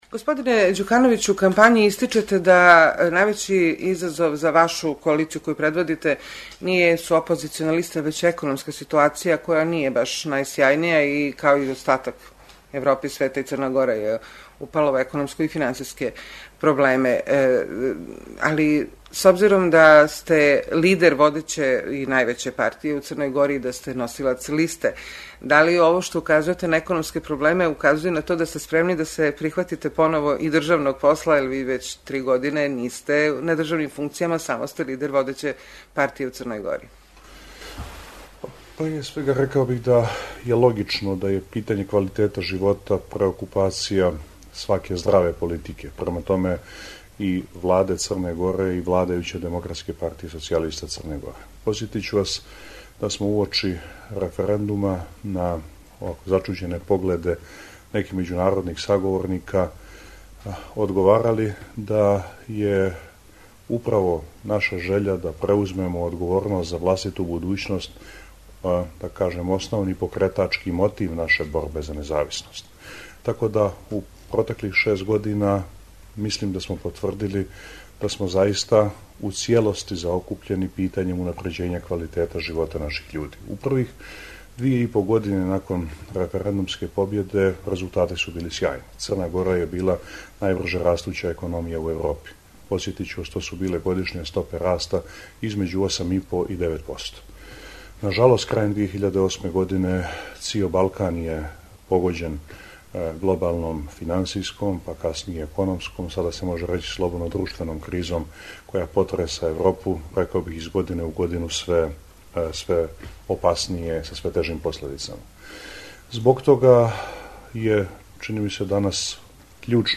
Интервју: Мило Ђукановић